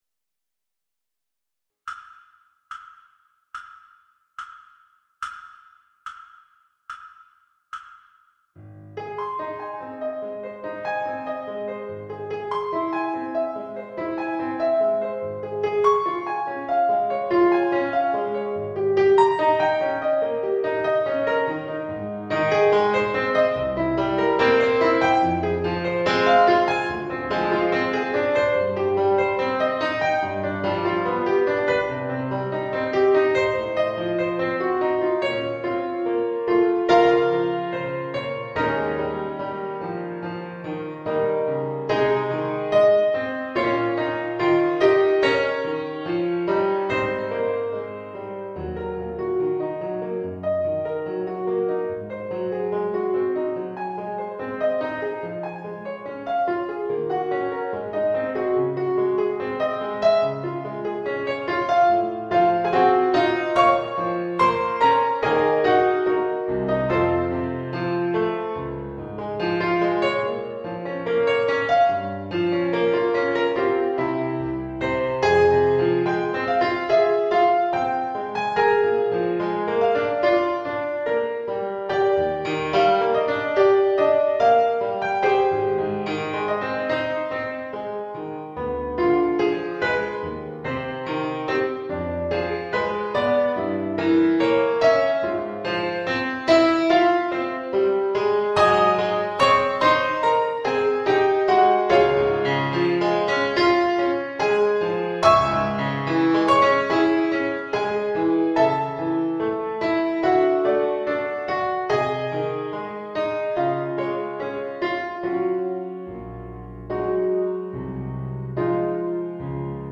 Sonata un poco Adagio backing track